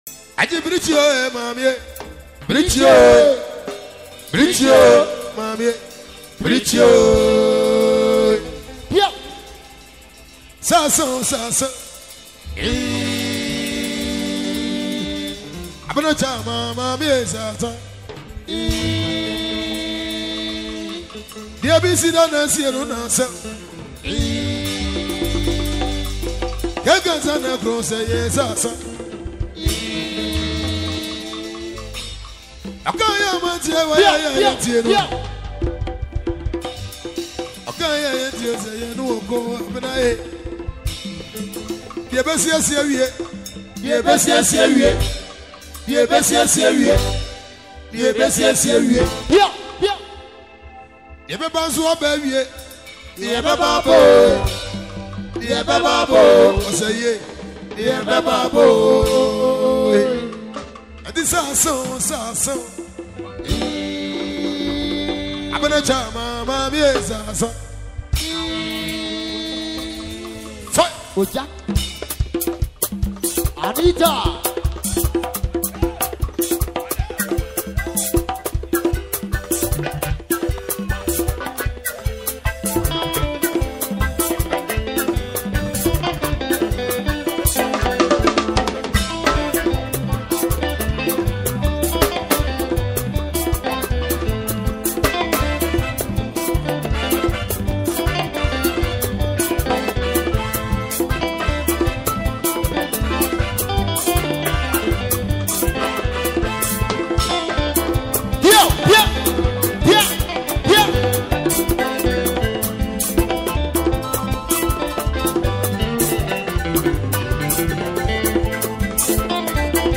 If you love Ghanaian music
This catchy song is a great example of the vibrant